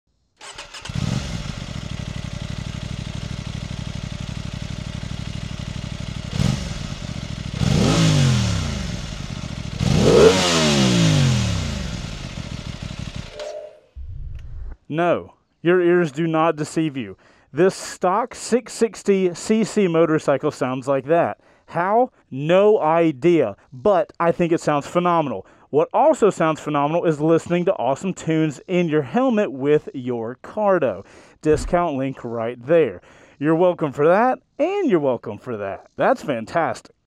The Tuono 660 Factory has that crisp, raspy tone that makes you want to keep twisting the throttle just to hear it again. Not over the top, but enough to grab your attention.